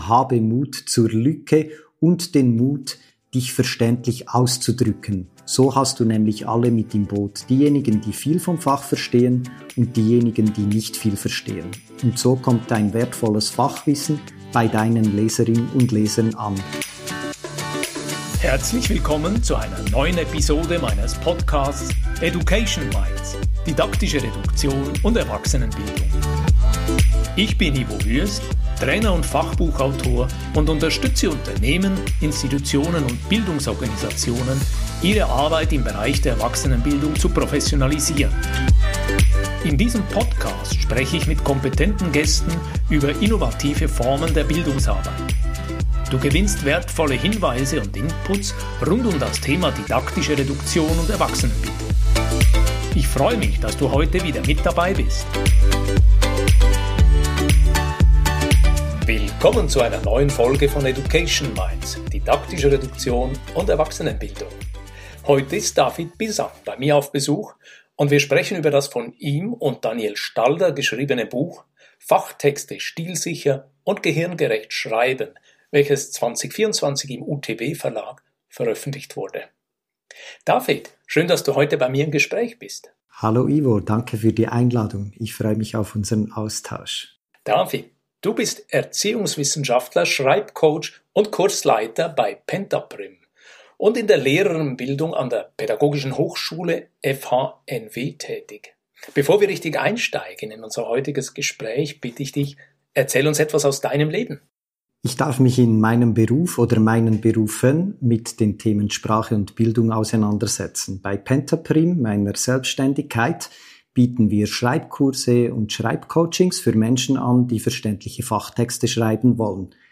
Ein hörenswertes Gespräch für alle, die Fachtexte schreiben – in Bildung, Wissenschaft, Verwaltung oder Unternehmen – und dabei verständlich, wirksam und professionell kommunizieren wollen.